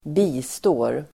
Uttal: [²b'i:stå:r]